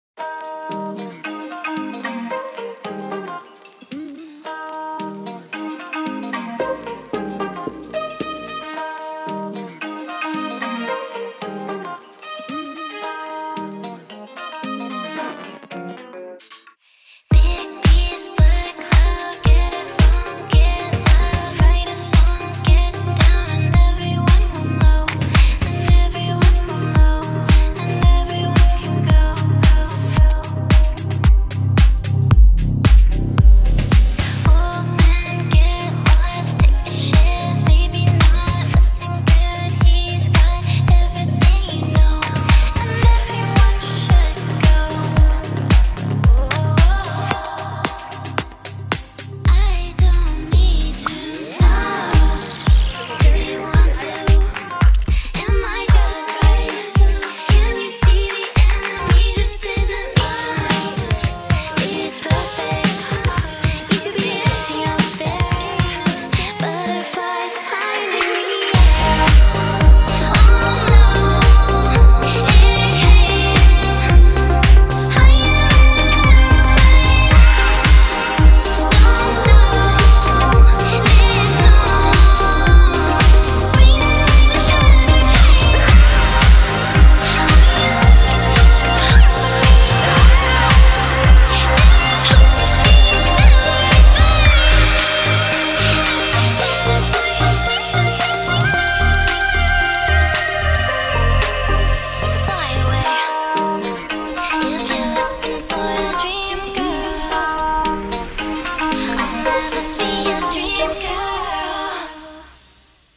BPM112
Audio QualityPerfect (High Quality)
Comments[ALTERNATIVE POP]